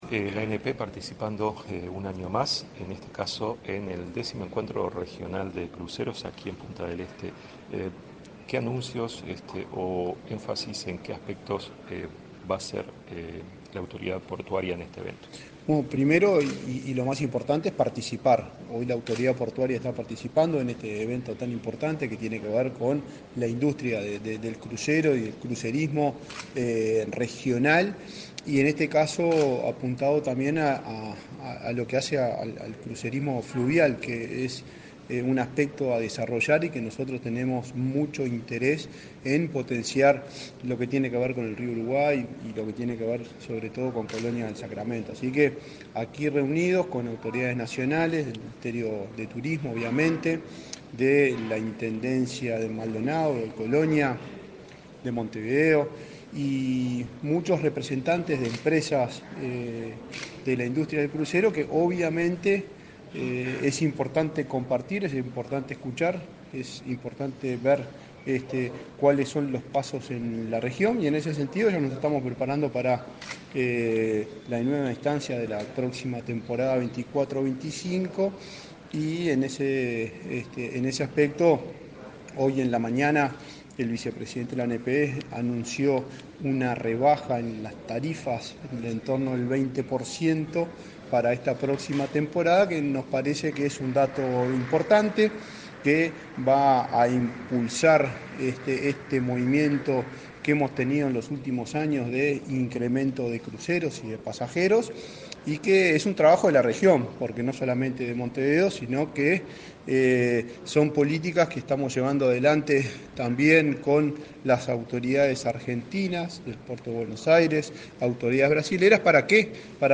Entrevista al presidente de la ANP, Juan Curbelo
Entrevista al presidente de la ANP, Juan Curbelo 01/08/2024 Compartir Facebook X Copiar enlace WhatsApp LinkedIn El X Encuentro Regional de Cruceros y Turismo Náutico Fluvial se realiza en Punta del Este el 1 y 2 de agosto. El presidente de la Administración Nacional de Puertos (ANP), Juan Curbelo, en declaraciones a Comunicación Presidencial, contó los detalles del encuentro.